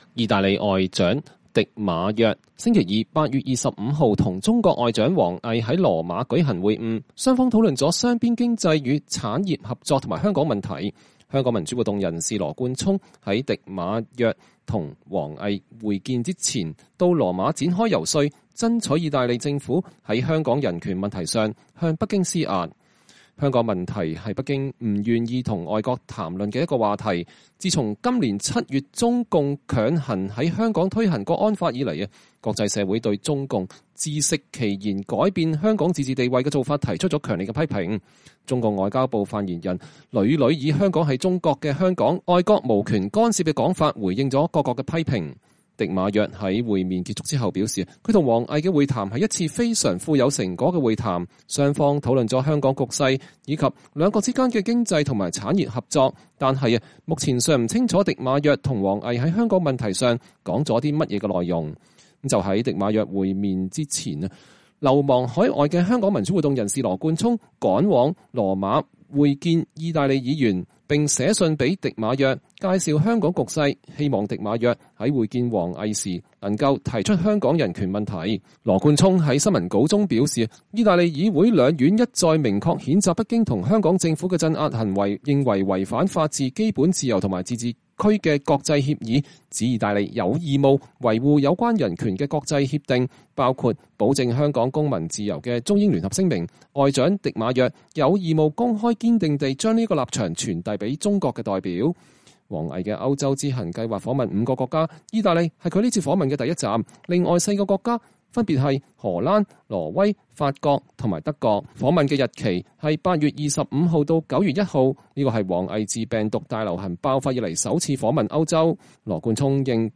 在中意兩國外長會晤期間，香港民主活動人士羅冠聰在意大利外交部外對媒體講話向羅馬施壓。